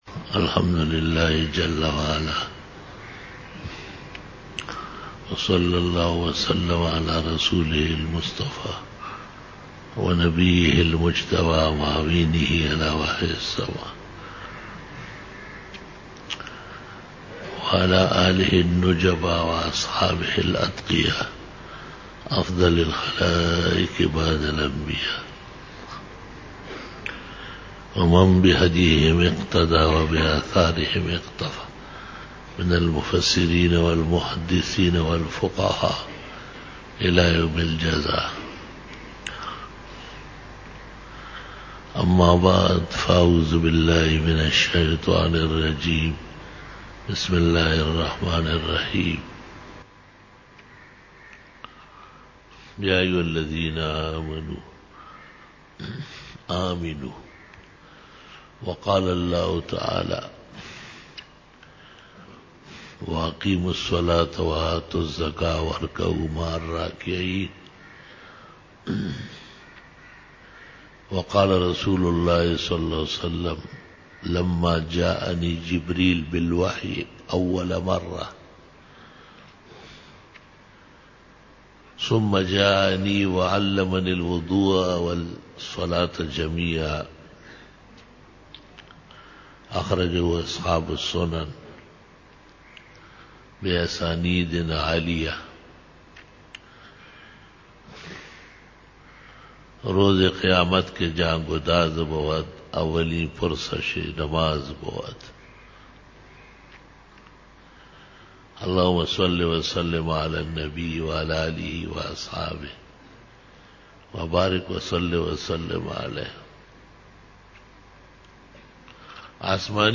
05_BAYAN E JUMA TUL MUBARAK (30-JANUARY-2015) (09 RabiUlSaani 1436h)